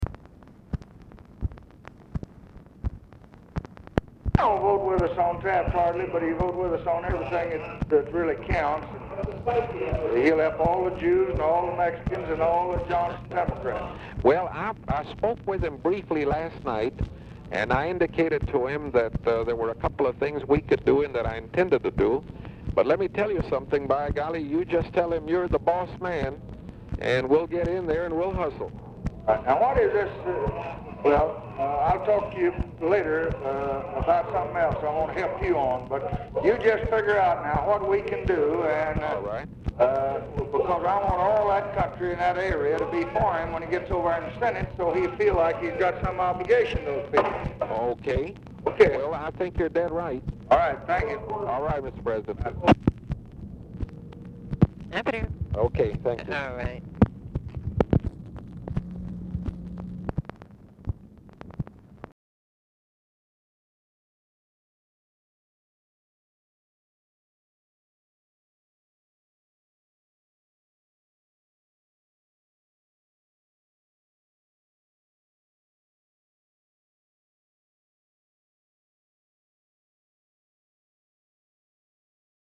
Telephone conversation # 10409, sound recording, LBJ and HENRY B. GONZALEZ, 7/14/1966, 6:52PM
RECORDING STARTS AFTER CONVERSATION HAS BEGUN; LBJ ON SPEAKERPHONE?; TV OR RADIO AUDIBLE IN BACKGROUND
OFFICE NOISE